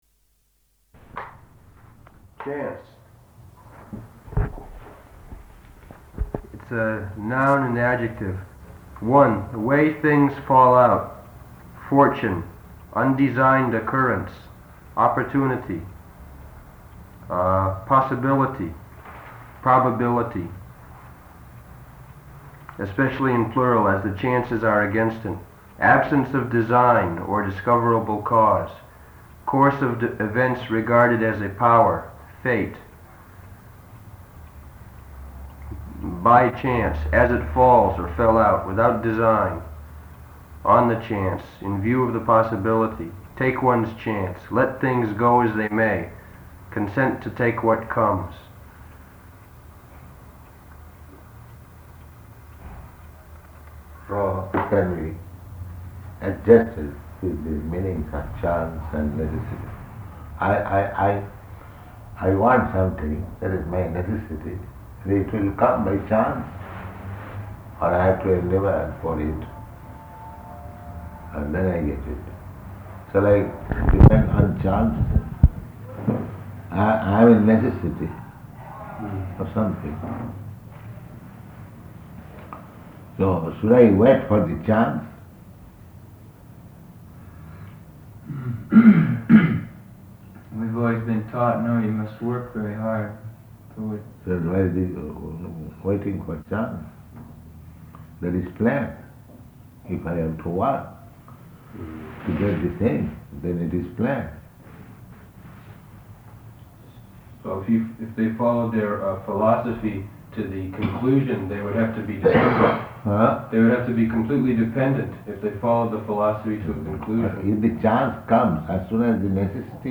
Room Conversation, "Definition of Chance"
-- Type: Conversation Dated: May 5th 1972 Location: Kyoto Audio file